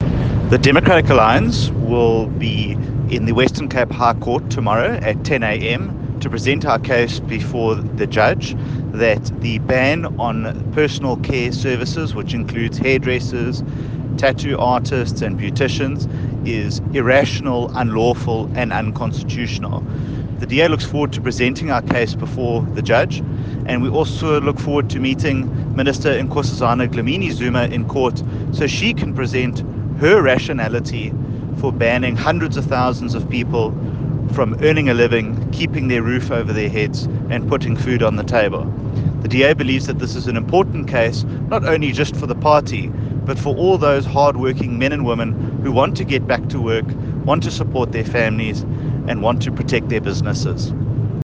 soundbite by Dean Macpherson, DA Shadow Minister of Trade and Industry.